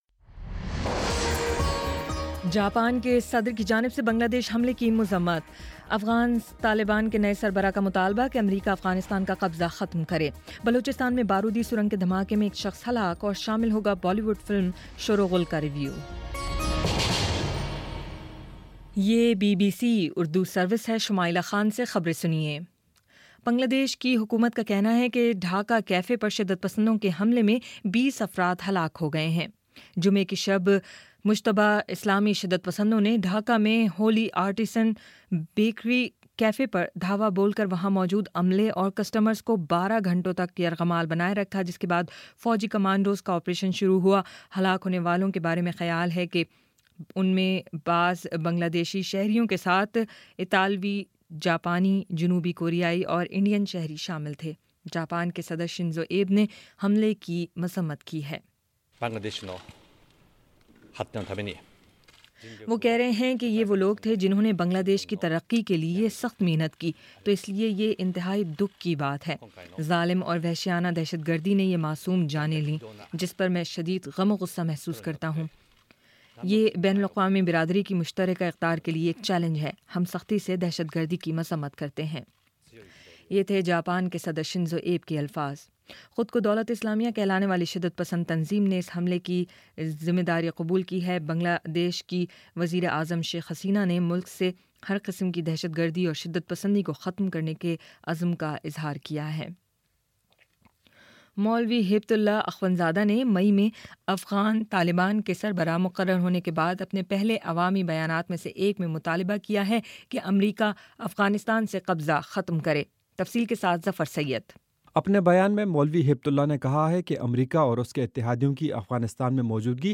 جولائی 02 : شام چھ بجے کا نیوز بُلیٹن